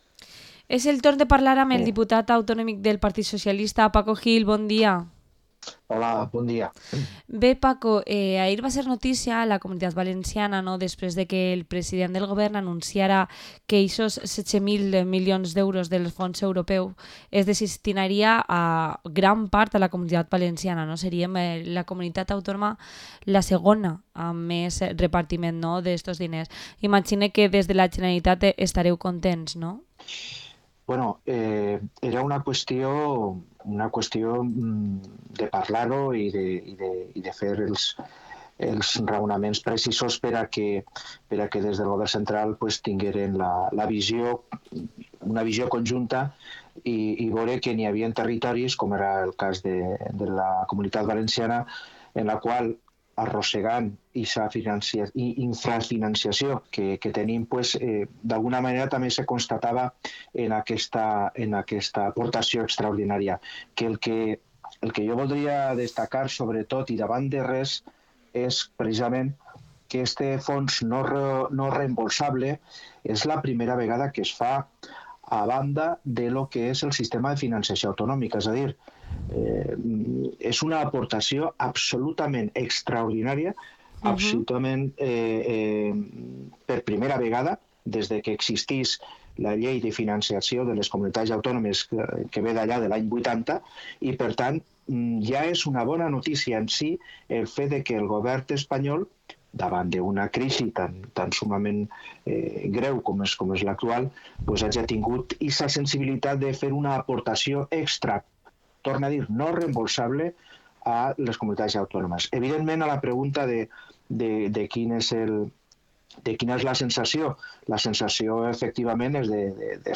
Entrevista al diputado autonómico del PSPV-PSOE, Paco Gil